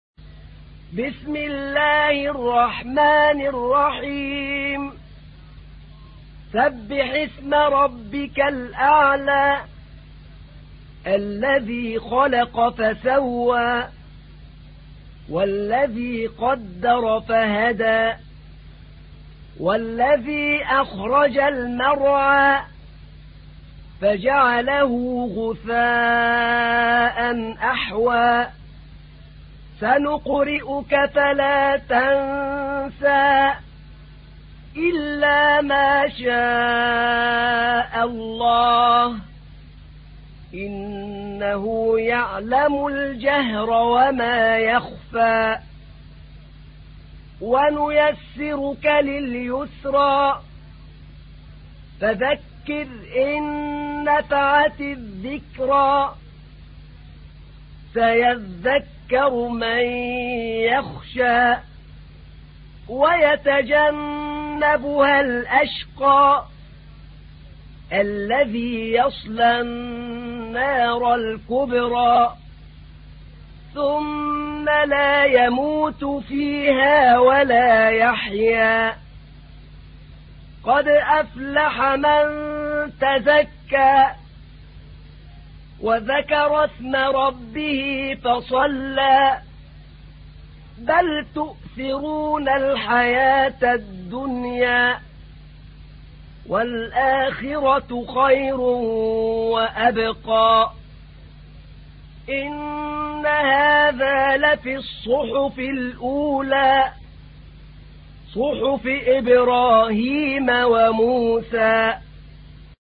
تحميل : 87. سورة الأعلى / القارئ أحمد نعينع / القرآن الكريم / موقع يا حسين